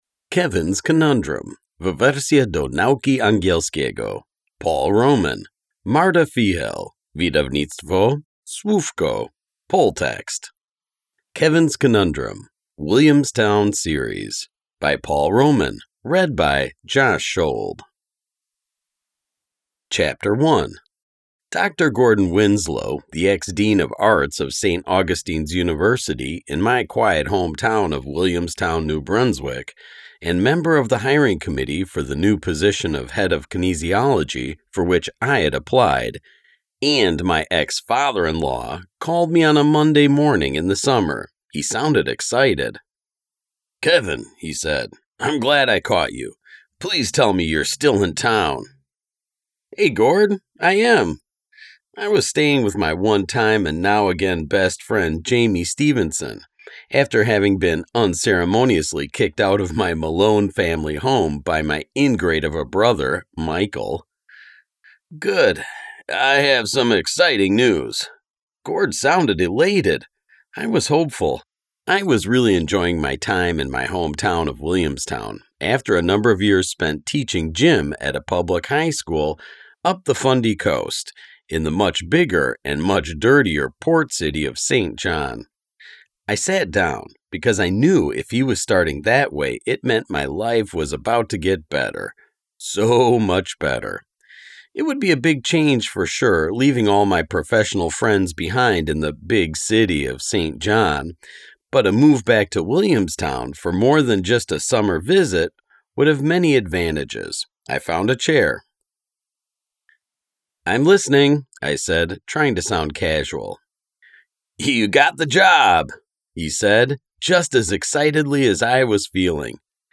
SŁUCHAJ – pobierz bezpłatne nagranie oryginalnego tekstu powieści, dostępne na stronie Wydawnictwa.